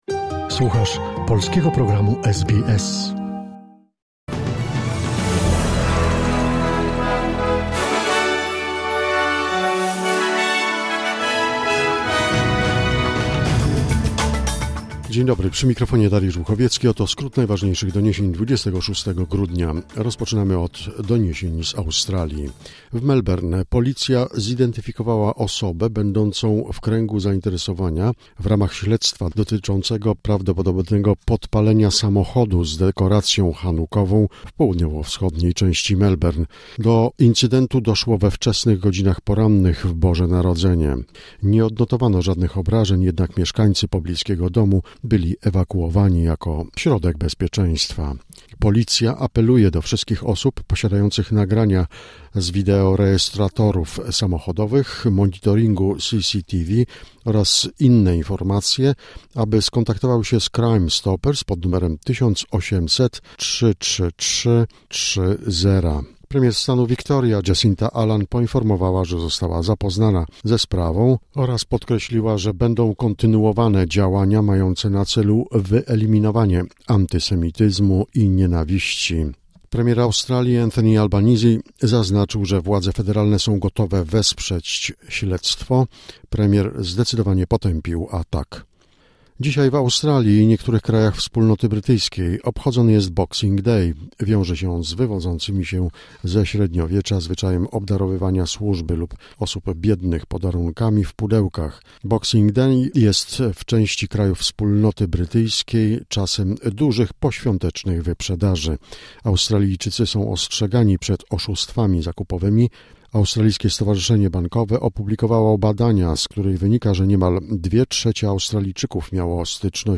Skrót najważniejszych doniesień z Australii i ze świata, w opracowaniu polskiej redakcji SBS.